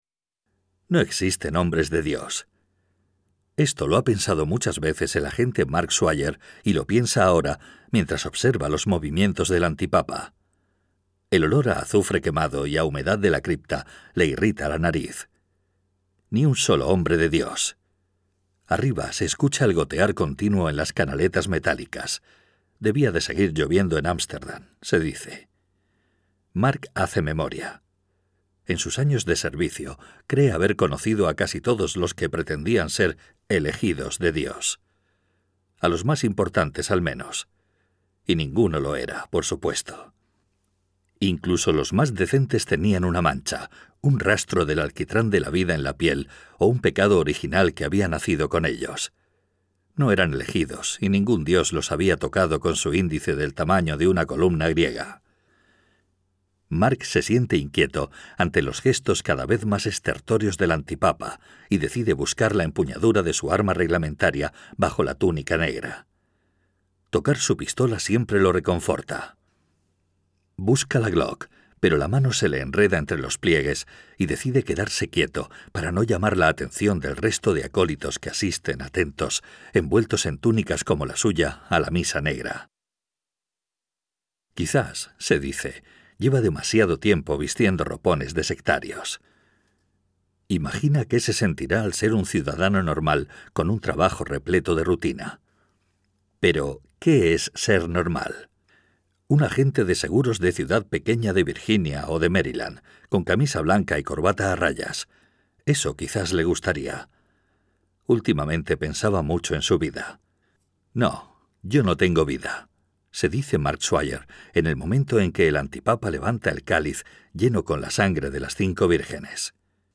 Audiolibro Los hijos de Eva (Eva's Children)